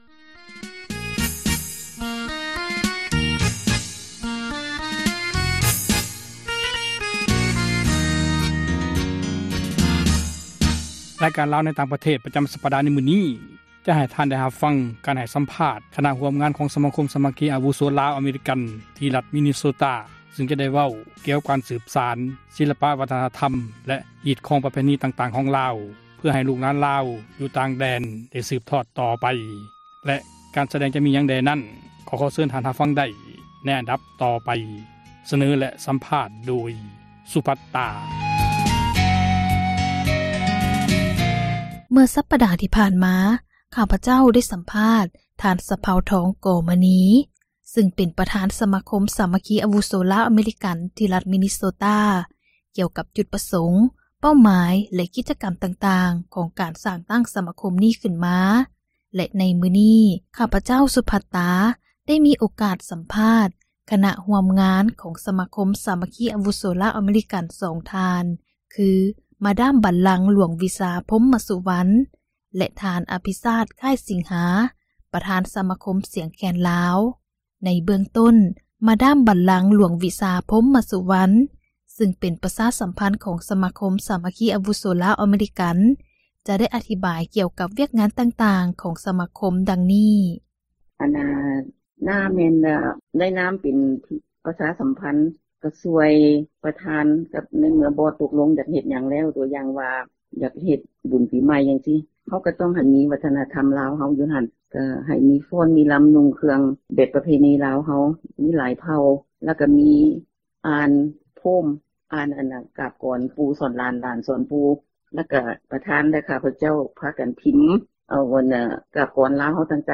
ຣາຍການ ”ລາວໃນຕ່າງປະເທດ” ປະຈຳສັປດາ ໃນມື້ນີ້ ຈະໃຫ້ທ່ານ ໄດ້ຮັບຟັງ ການໃຫ້ສຳພາດ ຄນະຜູ້ຮ່ວມງານ ຂອງ ສະມາຄົມ ສາມັກຄີ ອາວຸໂສ ລາວ-ອາເມຣິກັນ ທີ່ຣັຖ ມີນນີໂຊຕ້າ ຊຶ່ງຈະກ່າວ ກ່ຽວກັບ ການສືບສານ ສິລປະວັທນະທັມ ແລະຮິດຄອງປະເພນີ ຕ່າງໆ ຂອງລາວ ເພື່ອໃຫ້ລູກຫລານລາວ ຢູ່ຕ່າງແດນ ໄດ້ສືບທອດ ຕໍ່ໄປ...